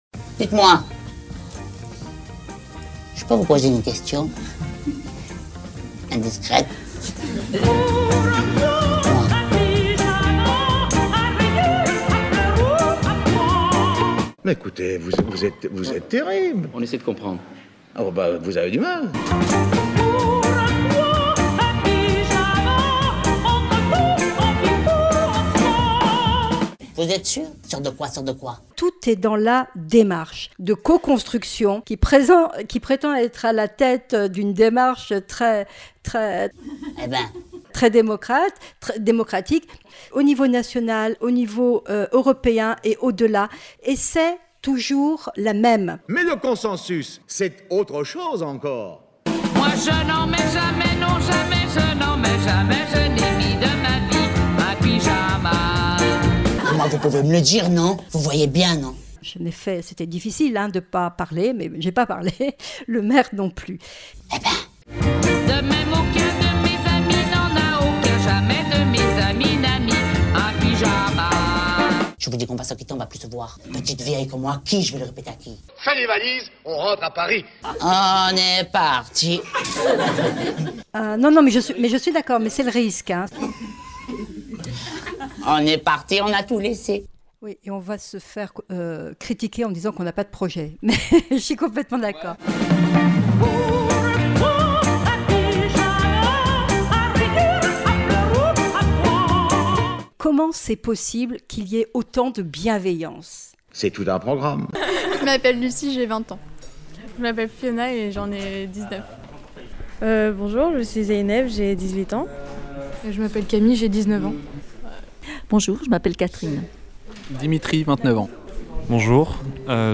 Mais en attendant ce moment d’anthologie radiothéâtrale, écoutez plutôt ceci, une immersion élective à la Faculté des Tanneurs…